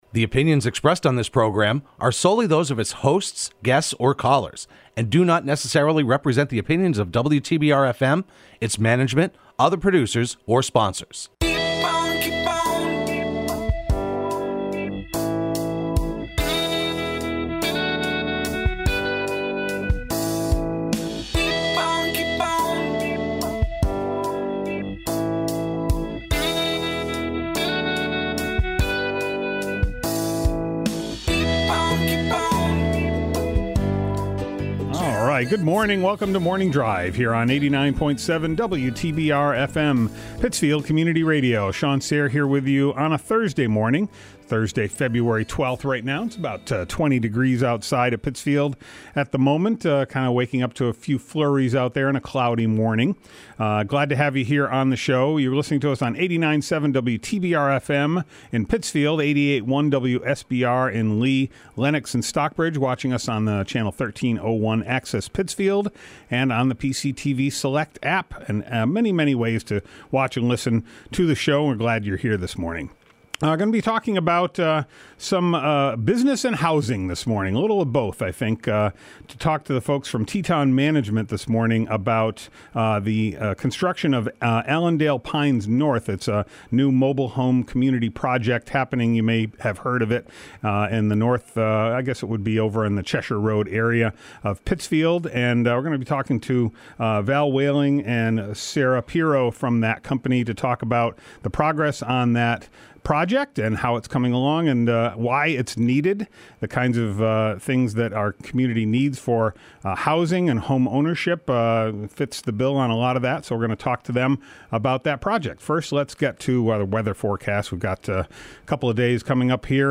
into the studio for a live chat.